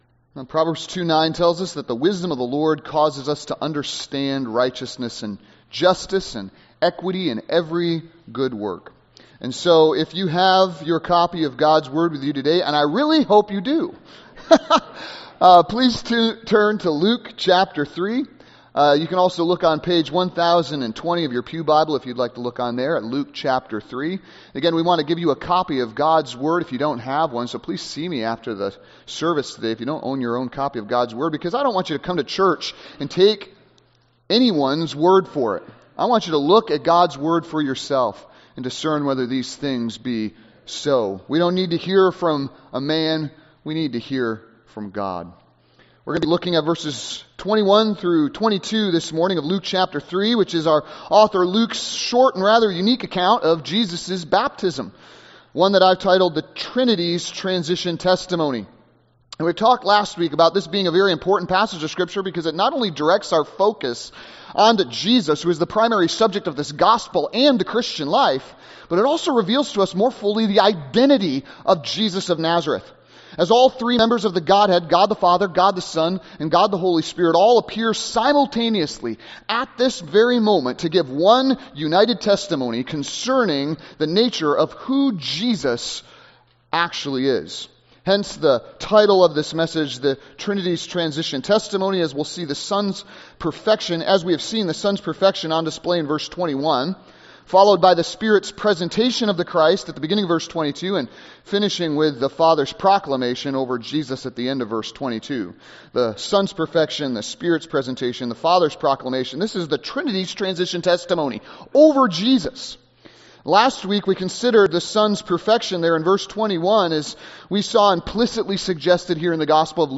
Sermons | Grace Chapel